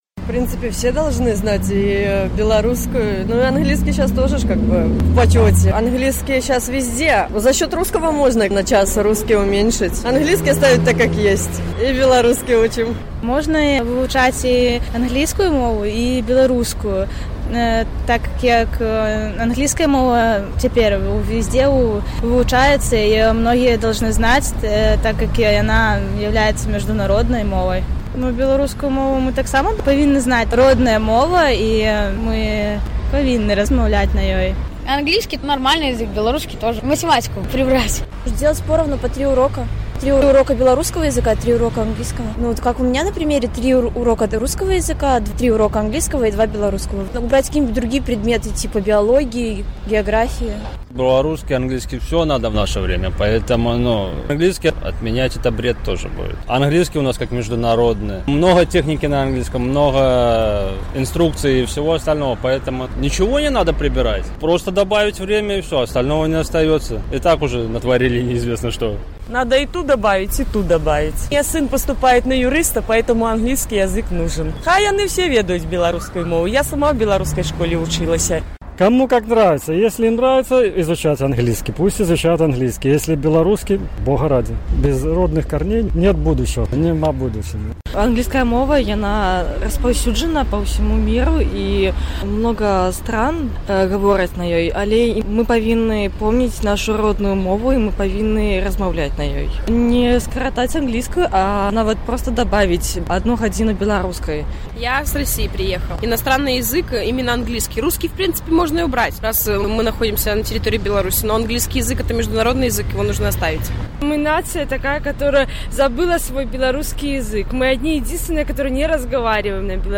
На гэтае пытаньне адказваюць жыхары Гомеля.